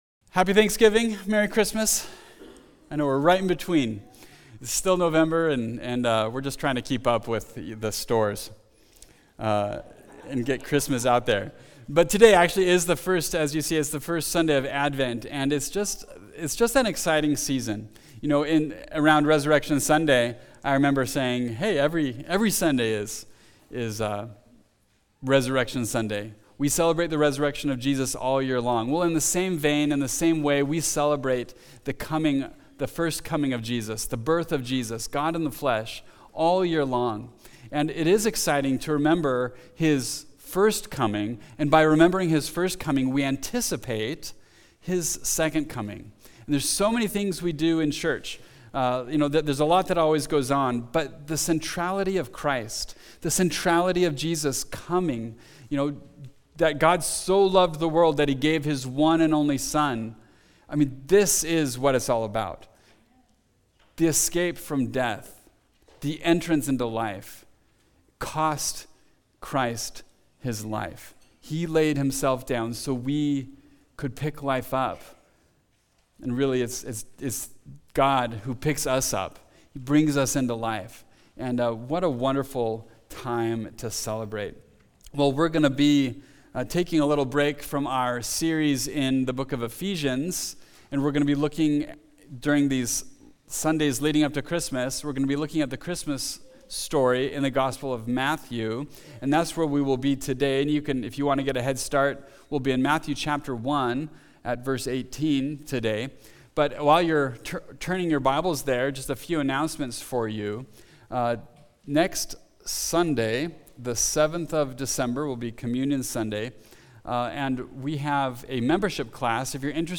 Christmas Message